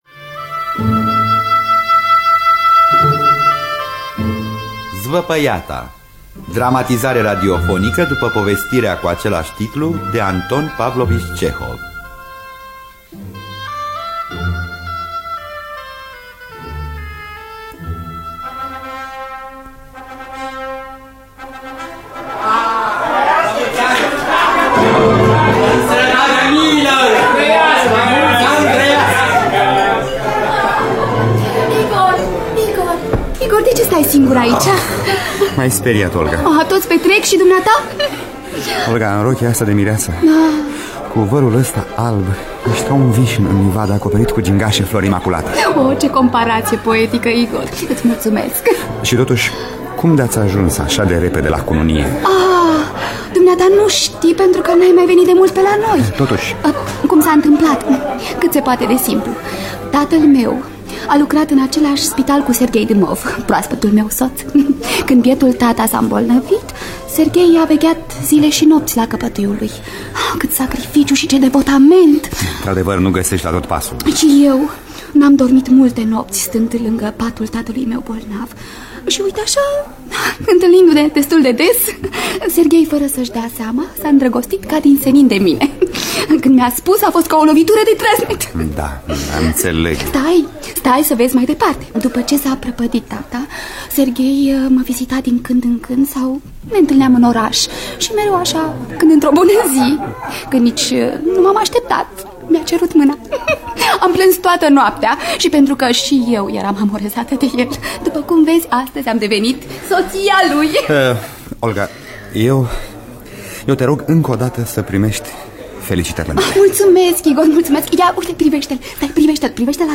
Zvăpăiata de Anton Pavlovici Cehov – Teatru Radiofonic Online